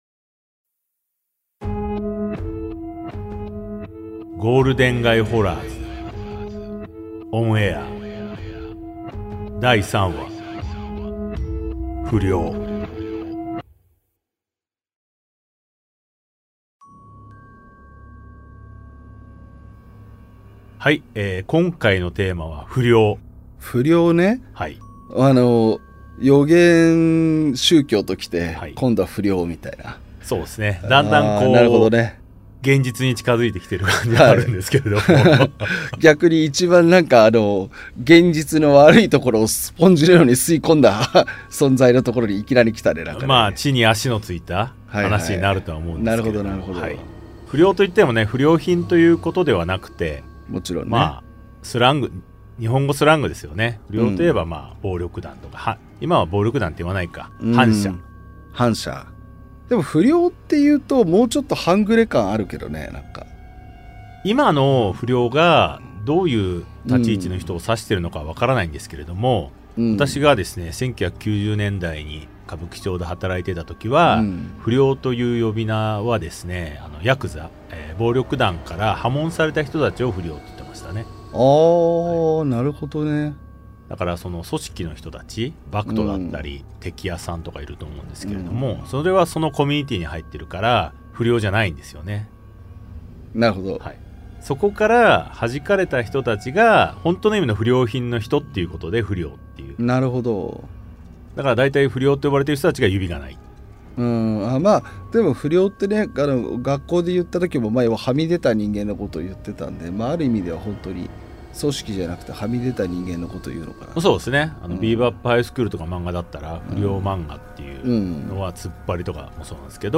[オーディオブック] ゴールデン街ホラーズ ON AIR vol.03 不良
対談形式のホラー番組
怪談師/作家。